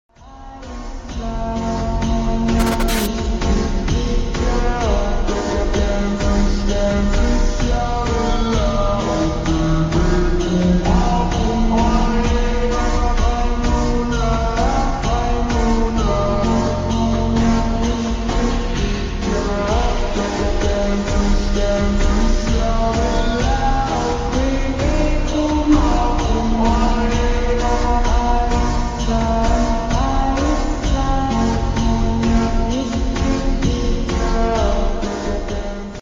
Bombing of Berlin Pengeboman Berlin sound effects free download